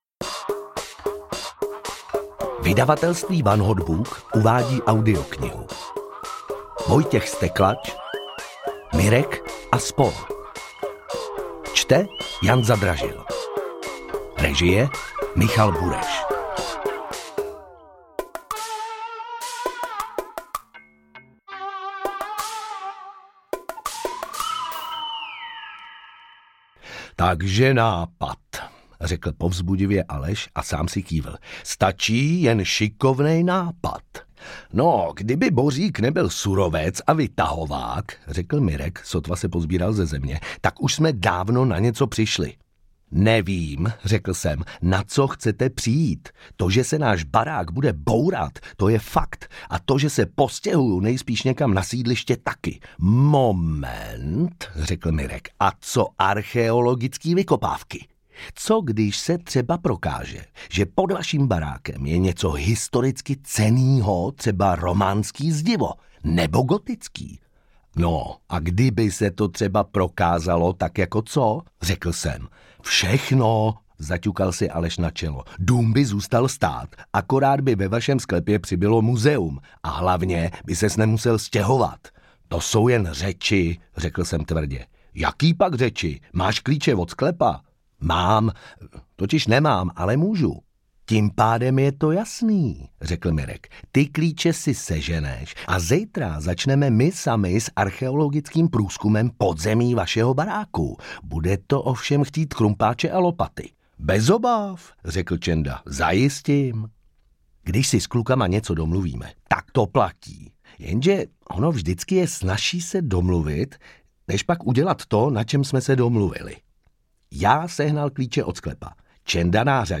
Mirek & spol audiokniha
Ukázka z knihy